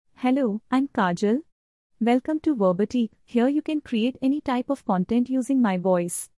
Kajal — Female Indian English AI voice
Kajal is a female AI voice for Indian English.
Voice sample
Listen to Kajal's female Indian English voice.
Kajal delivers clear pronunciation with authentic Indian English intonation, making your content sound professionally produced.